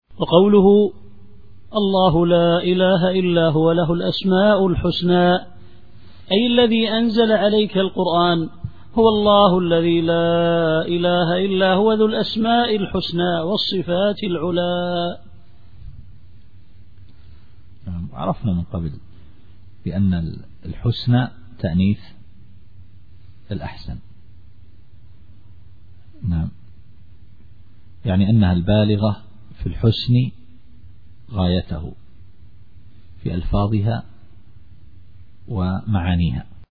التفسير الصوتي [طه / 8]